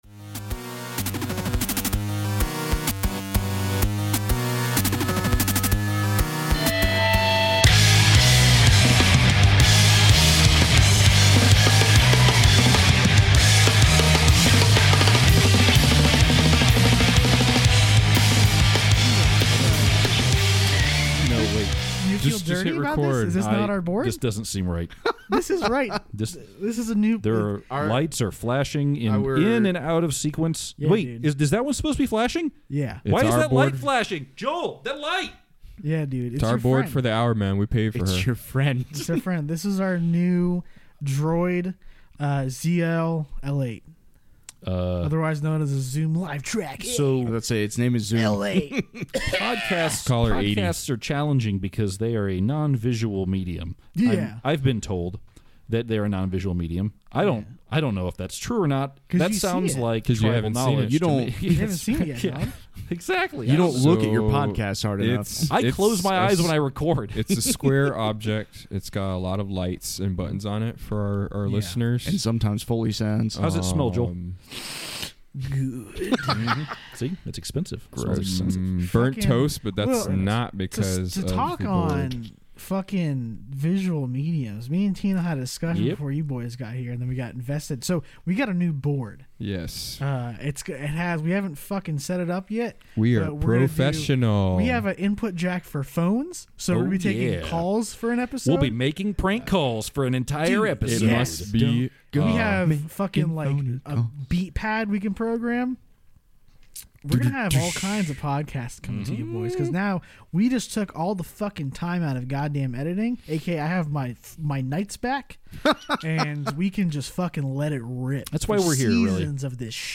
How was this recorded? New recording interface.